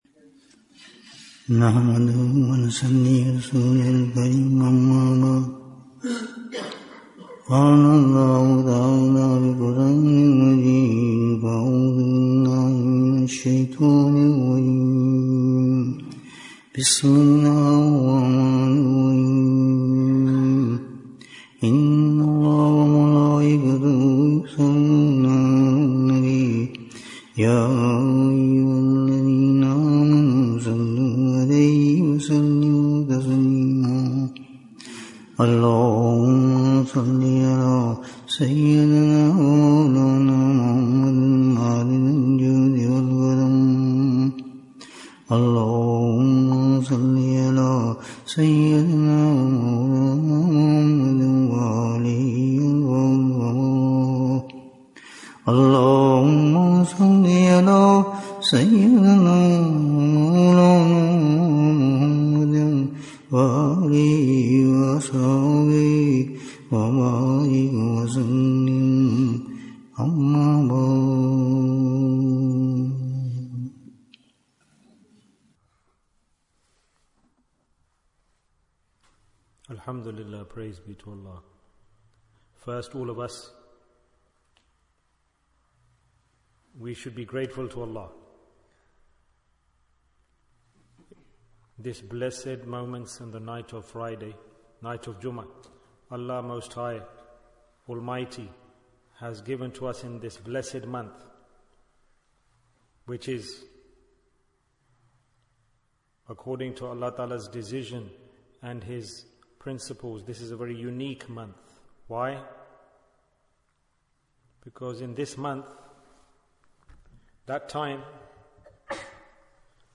The One With Light has Come Bayan, 67 minutes12th September, 2024